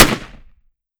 7Mag Bolt Action Rifle - Gunshot A 003.wav